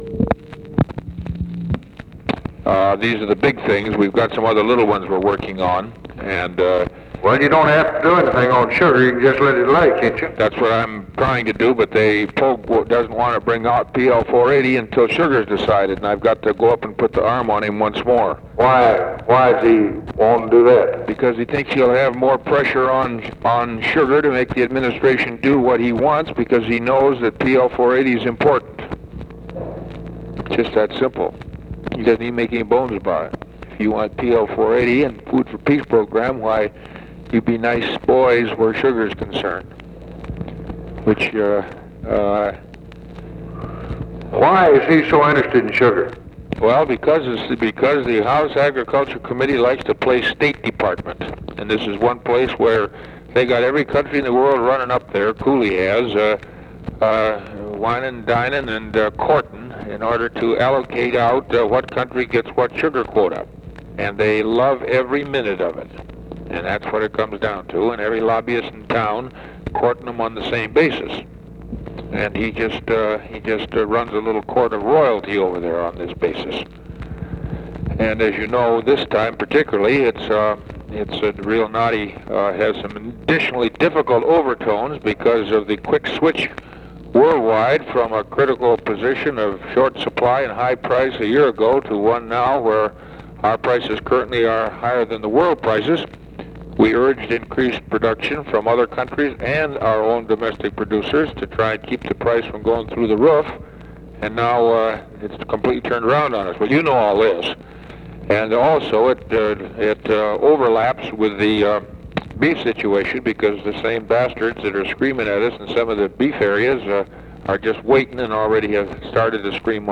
Conversation with ORVILLE FREEMAN, June 30, 1964
Secret White House Tapes